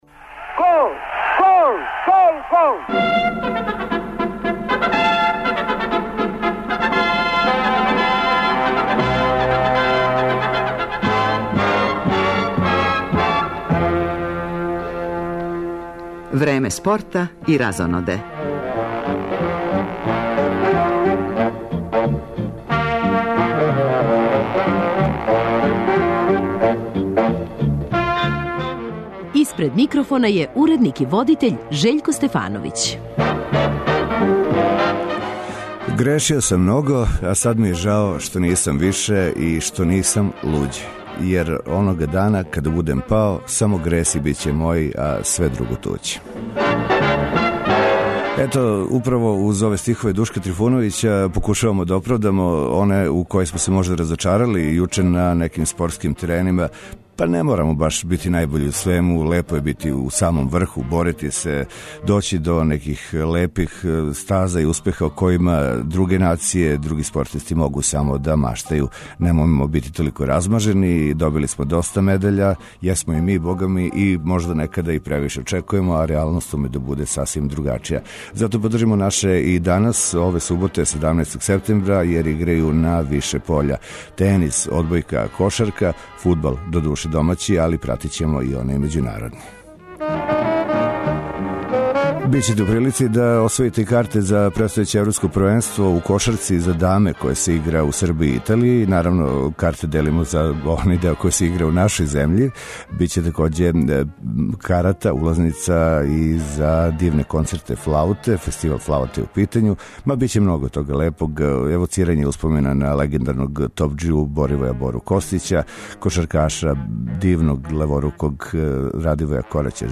Породични магазин и овог викенда препун је укључења и извештаја с атрактивних спортских догађаја из земље и света. У центру пажње је тенис, полуфинални меч Дејвис купа између селекција Србије и Аргентине, данас је на програму меч дублова.
Саставни део ове емисије су преноси домаћих фудбалских утакмица, па ћемо и данас комбиновано пратити утакмице Супер лиге.